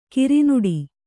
♪ kirinuḍi